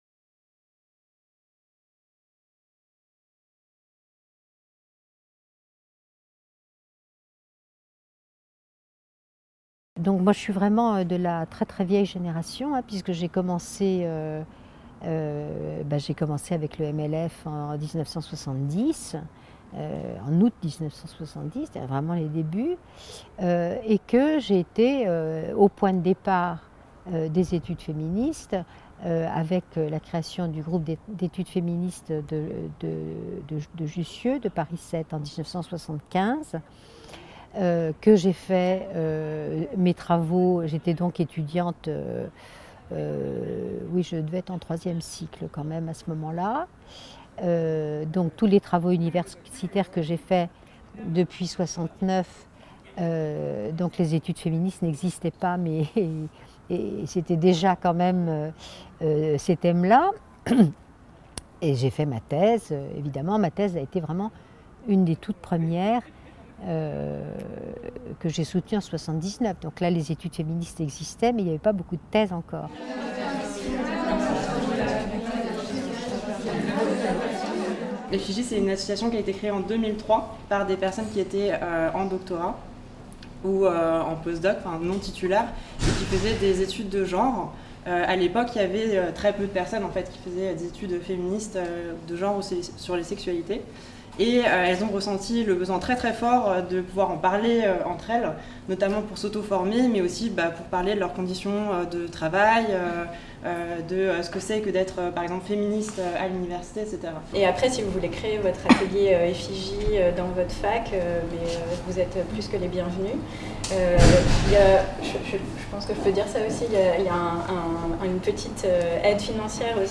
Ce film restitue, à travers une série d’interviews de participant·e·s, la diversité de ces projets et actions.